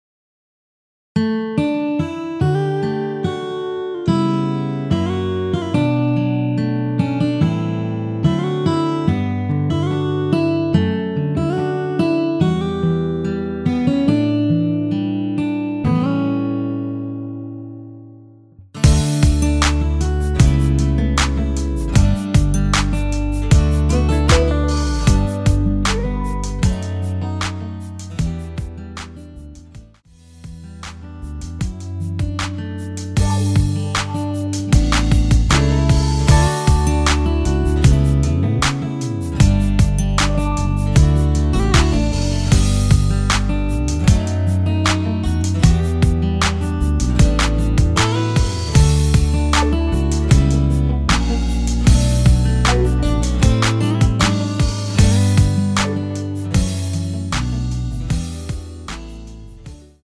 원키에서(-1)내린 (1절+후렴) MR입니다.
D
앞부분30초, 뒷부분30초씩 편집해서 올려 드리고 있습니다.
중간에 음이 끈어지고 다시 나오는 이유는